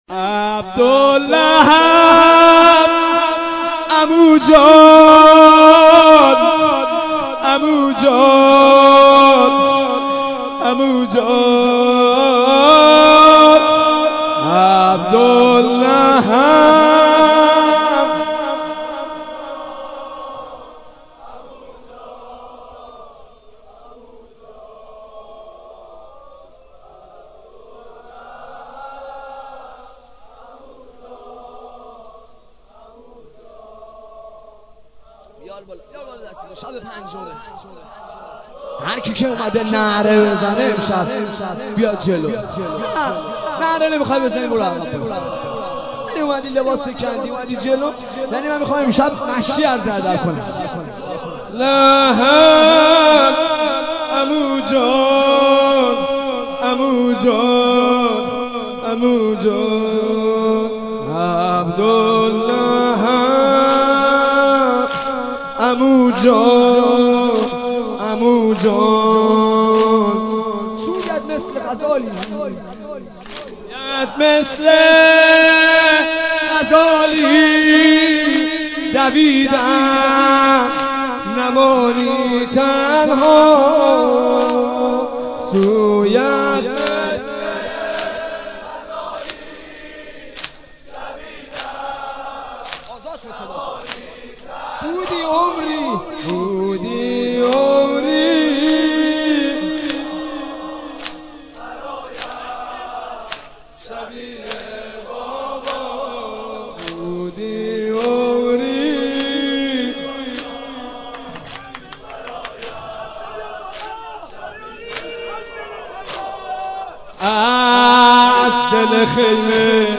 به سبک نوحه